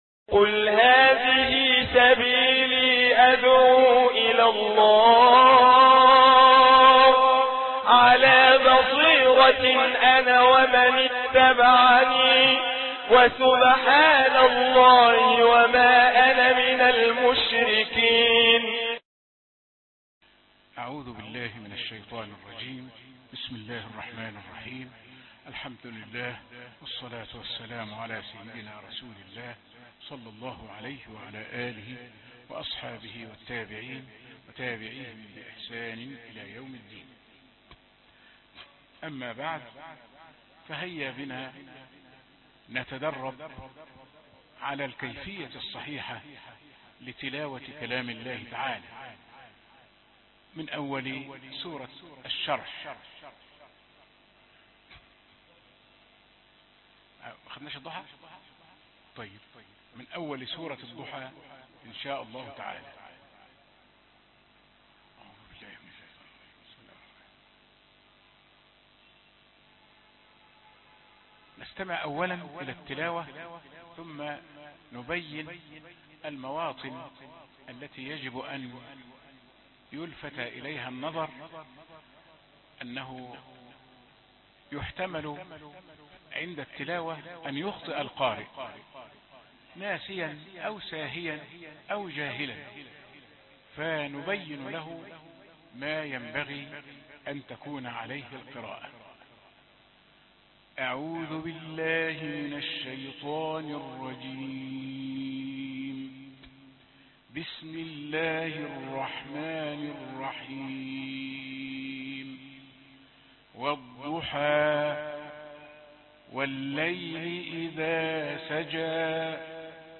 الدرس 36 من أول سورة الضحى - تيسير تلاوة القرآن برواية حفص